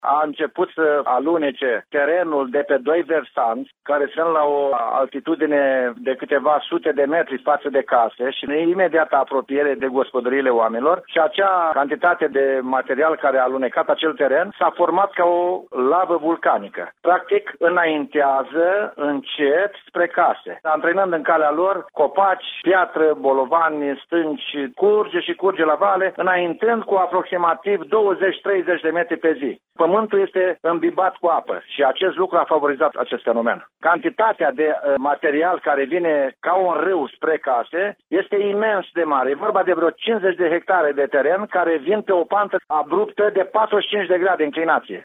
Primarul de Comănești, Viorel Miron, a declarat pentru postul nostru de radio că dimineață a fost convocat Comitetul Local pentru Situații de Urgență, iar ca primă măsură s-a decis evacuarea populației din zonă.